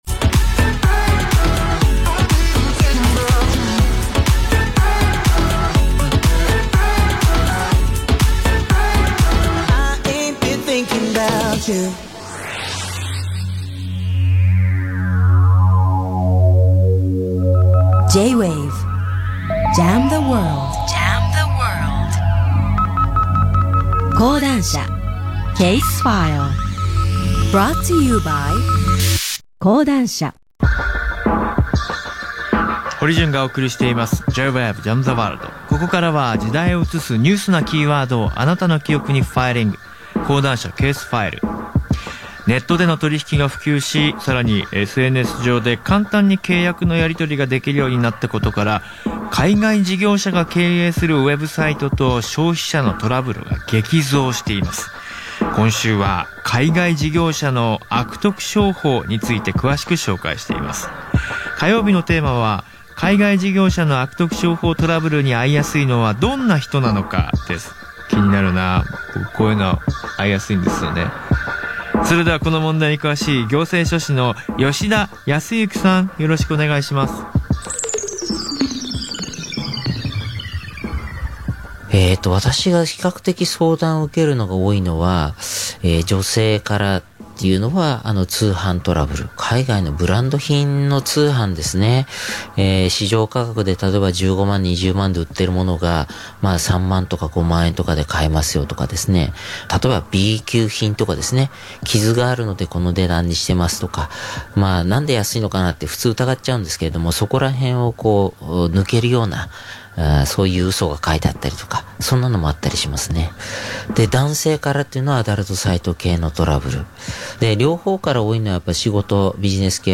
平成２９年２月１３日放送分